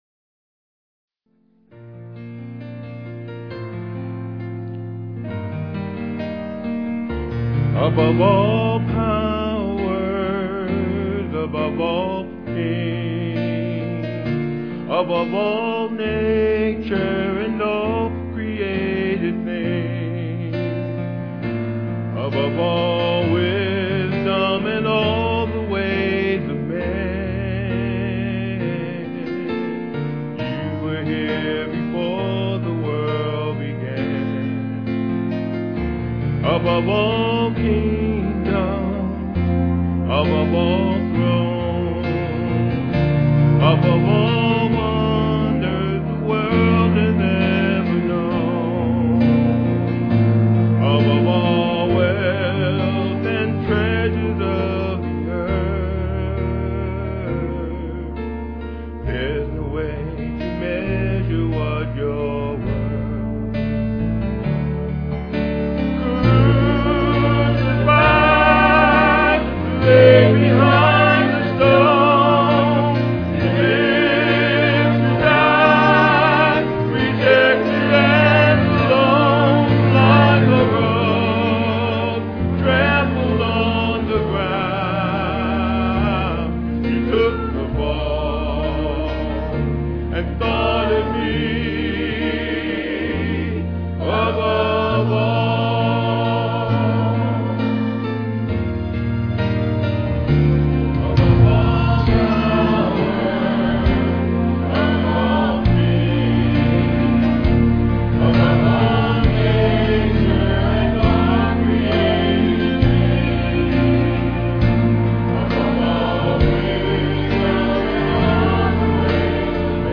Excerpts from "Written in Red" Easter Musical: "Written in Red" words and music by Gordon Jensen.
Piano offertory